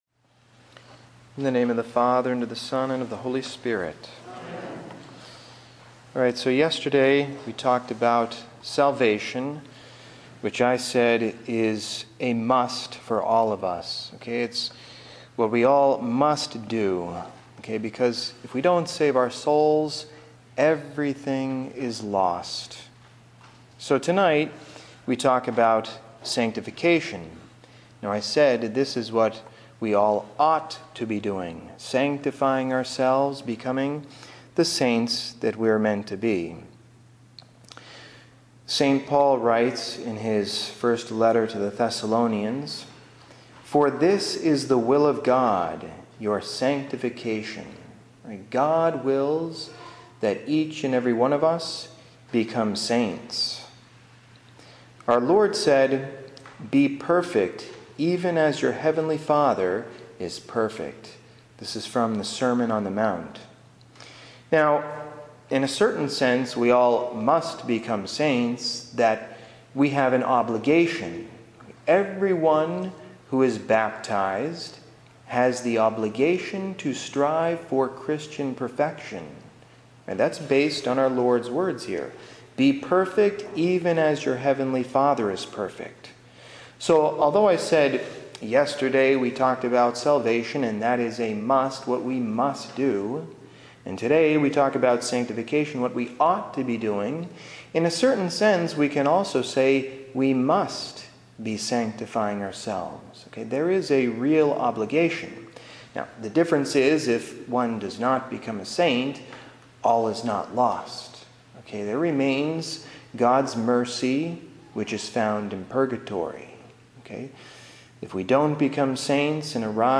Lenten Mission
March 19: Talk 2: Sanctify Your Soul Ave Maria!